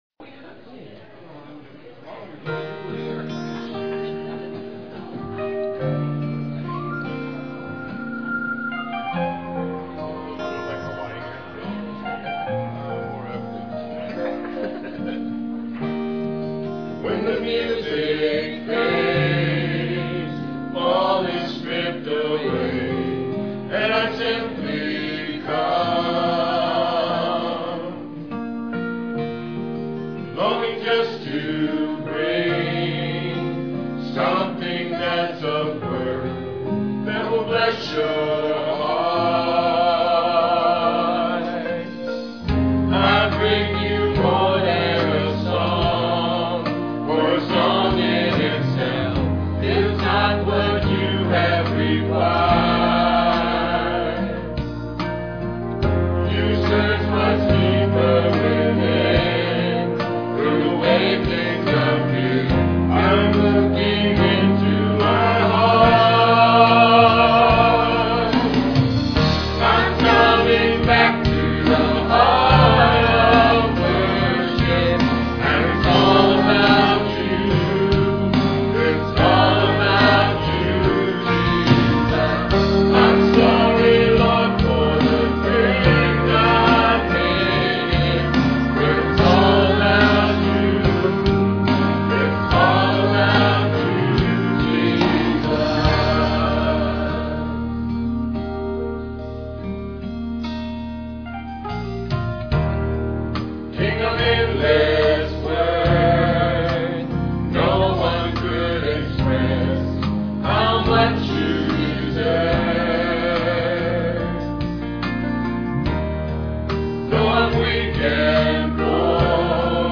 Guitar and vocal solo